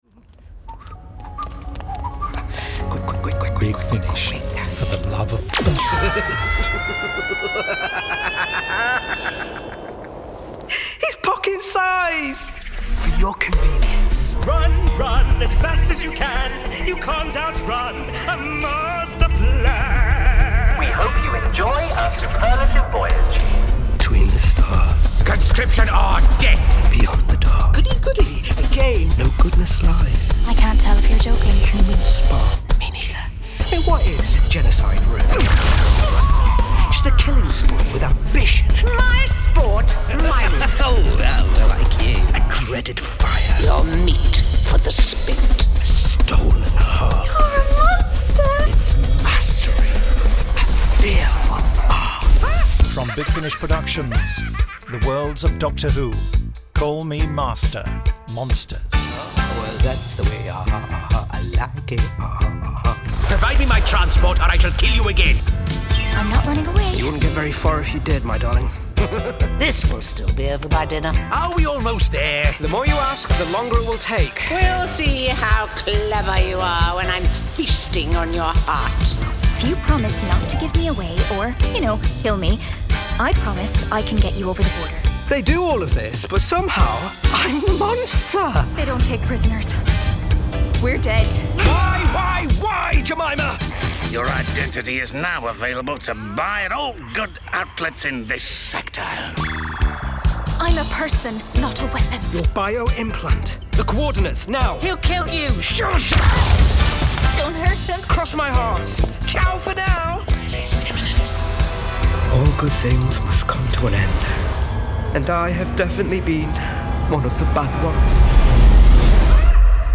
full-cast original audio dramas
Starring Sacha Dhawan